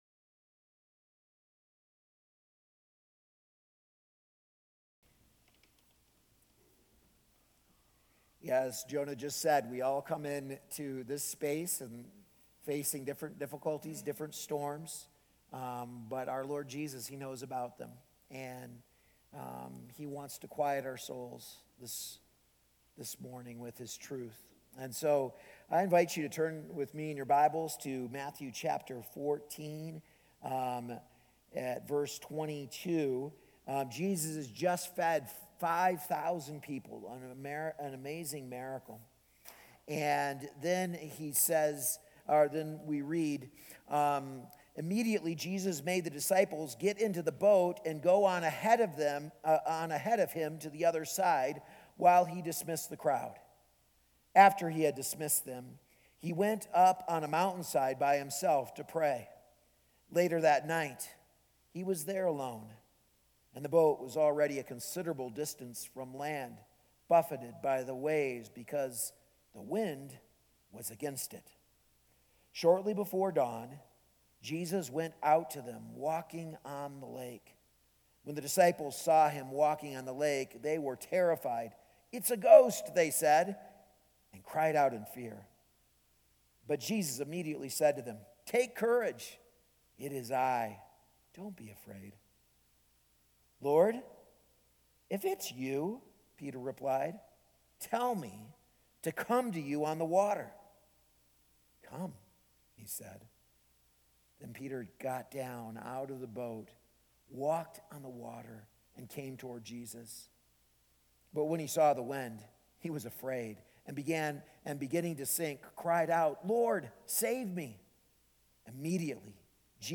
A message from the series "Encountering Christ."